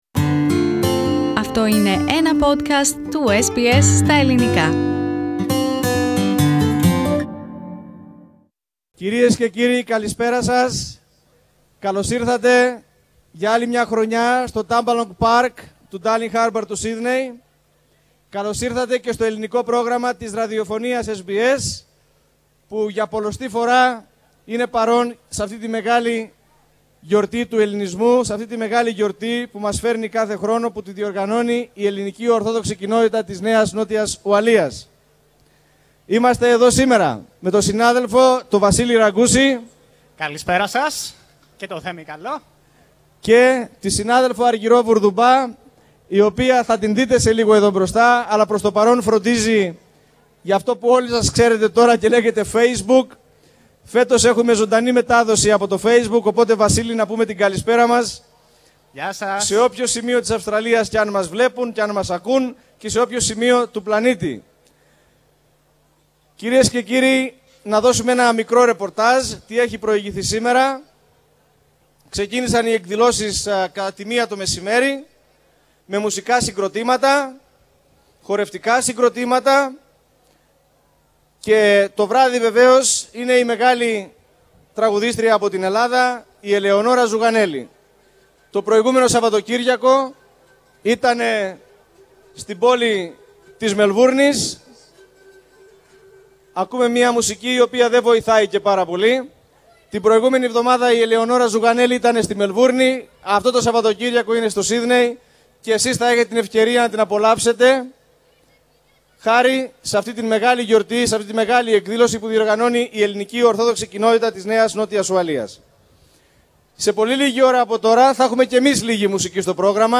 Την Κυριακή 3 Μαρτίου στην κεντρική σκηνή του Φεστιβάλ βρέθηκε και το Ελληνικό Πρόγραμμα της Ραδιοφωνίας SBS με εκλεκτούς καλεσμένους.